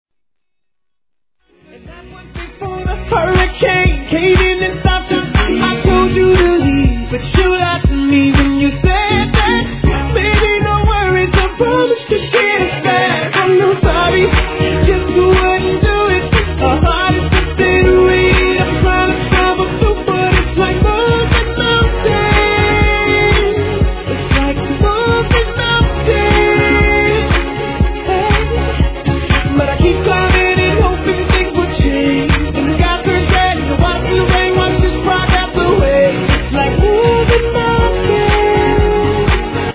1. HIP HOP/R&B
(BPM 120)